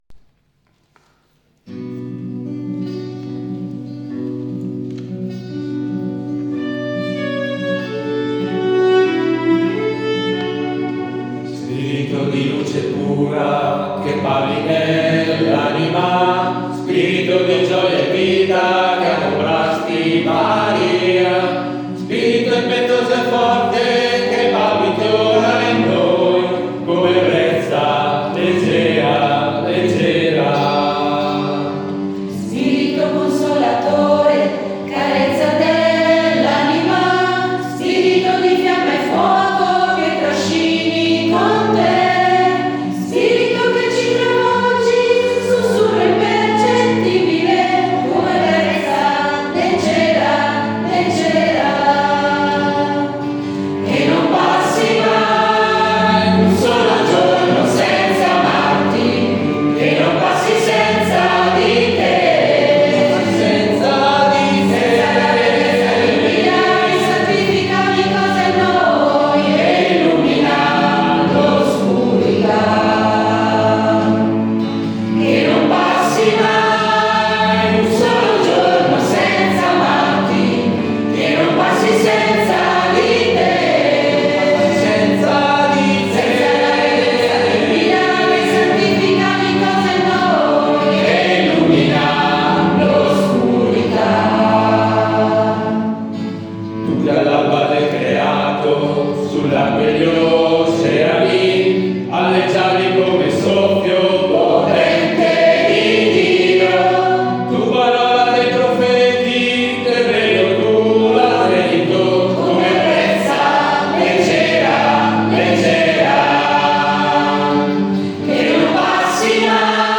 Il nostro Coro Giovani ha voluto dedicare il canto Come Brezza